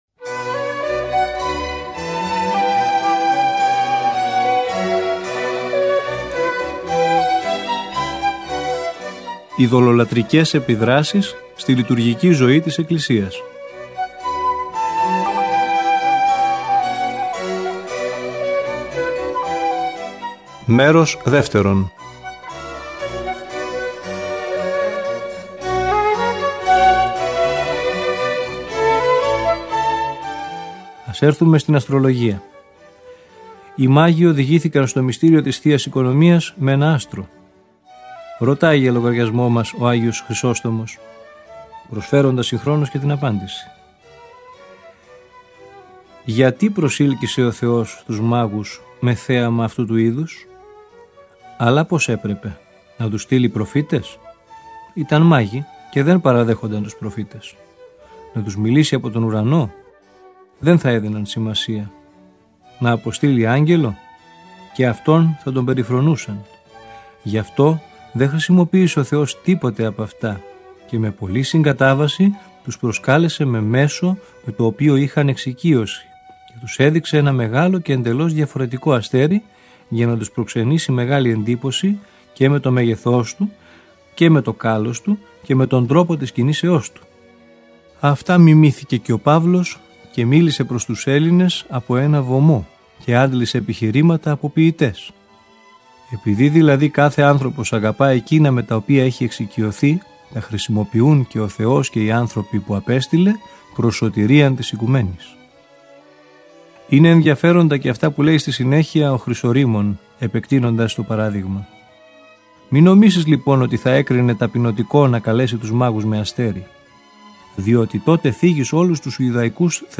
Ακούστε το επόμενο κείμενο, όπως αυτό «δημοσιεύθηκε» σε 2 τεύχη (136-ο, Ιουλίου – Αυγούστου και 137-ο Σεπτεμβρίου – Οκτωβρίου του 2012), του ηχητικού περιοδικού μας, Ορθόδοξη Πορεία.